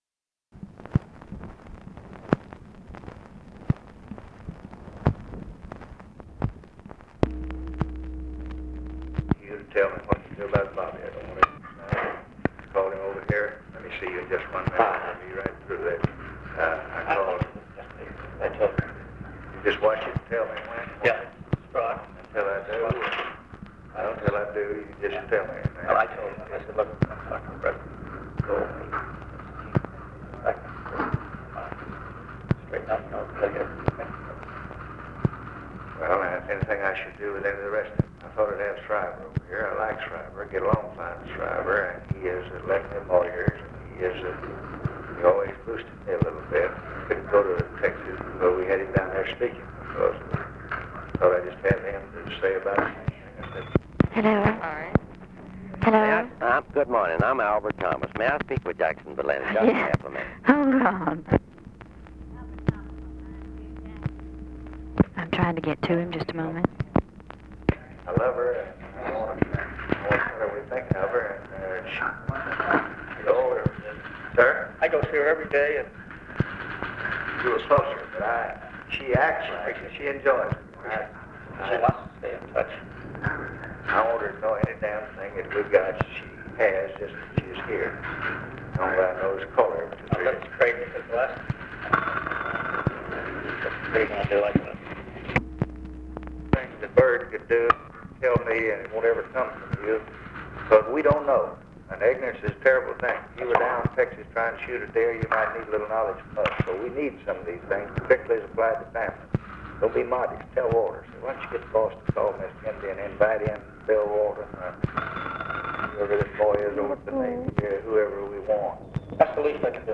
OFFICE CONVERSATION, December 11, 1963
Secret White House Tapes